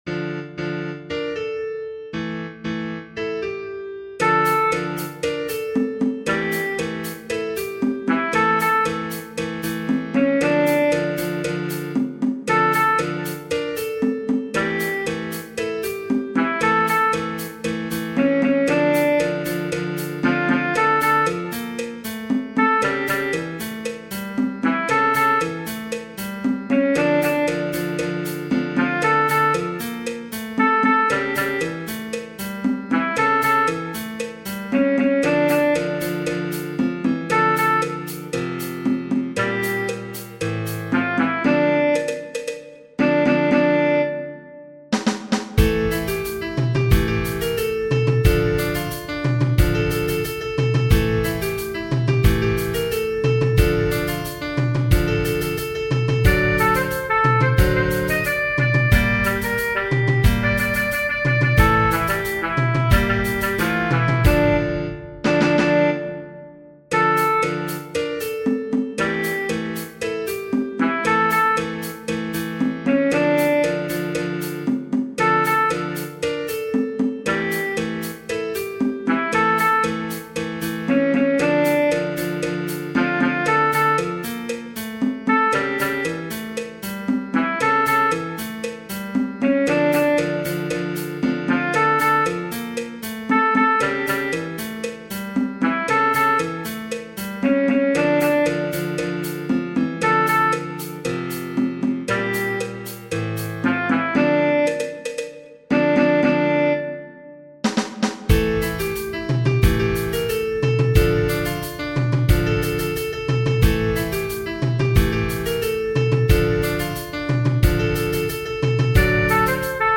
Genere: Folk